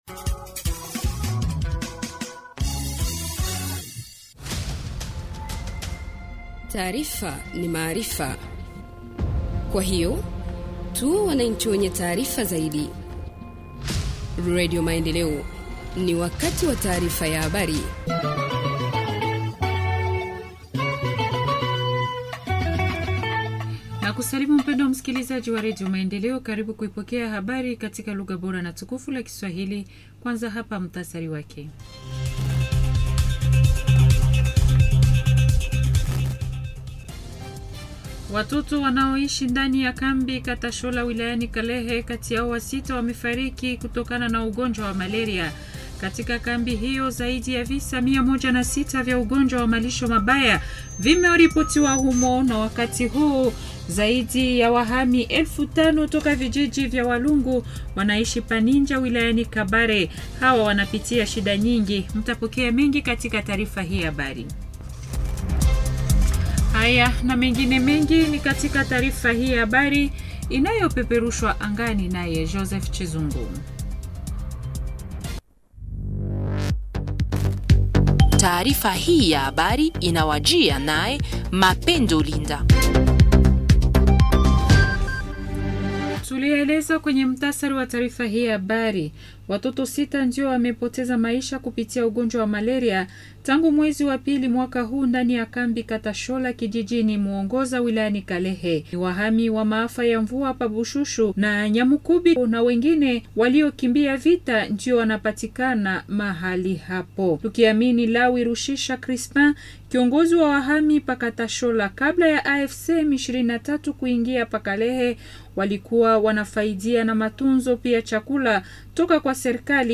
Journal en Swahili du 16 avril 2025 – Radio Maendeleo